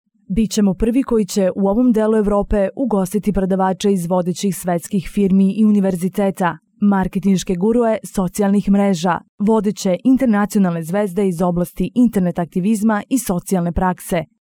Serbian – female – AK Studio